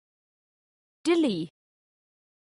the 101 vocalisations used in Canntaireachd